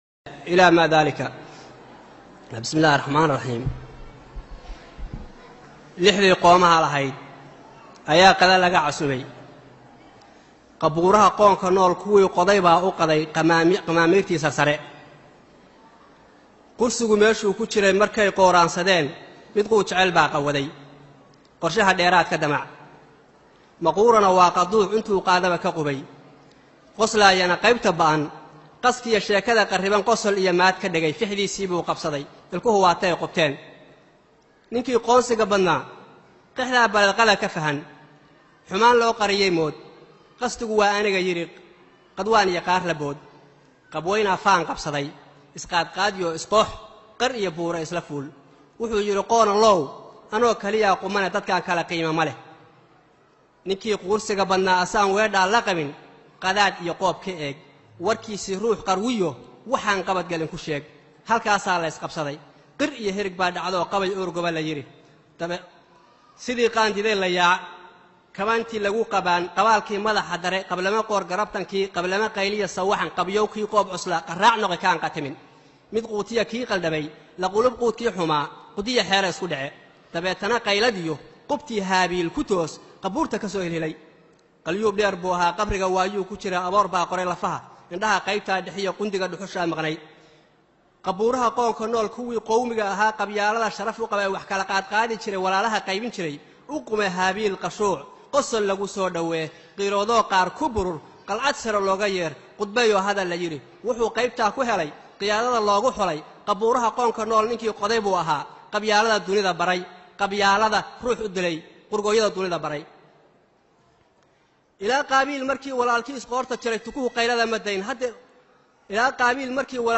Gabay - Qabuuraha qoomka nool